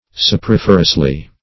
[1913 Webster] -- Sop`o*rif"er*ous*ly , adv.